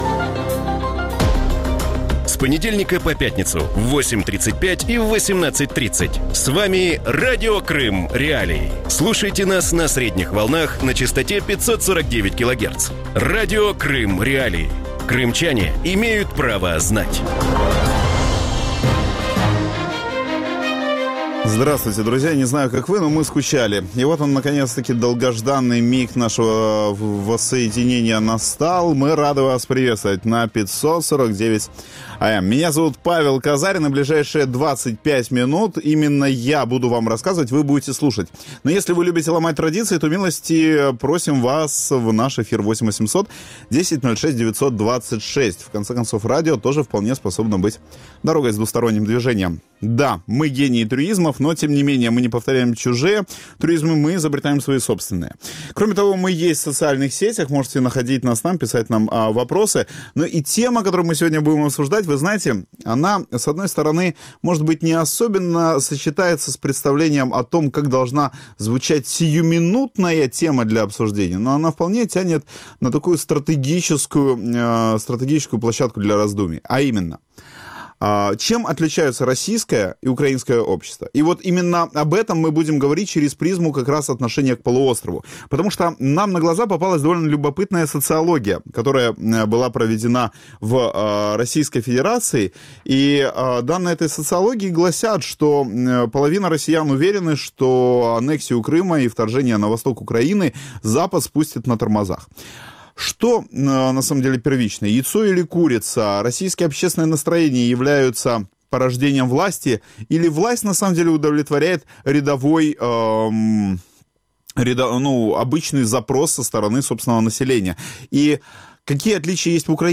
В вечернем эфире Радио Крым.Реалии обсуждают, могут ли россияне признать вину за аннексию Крыма и развязывание войны на Донбассе. Чем является российское общество – архитектором режима или его заложником.